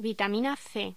Locución: Vitamina C
voz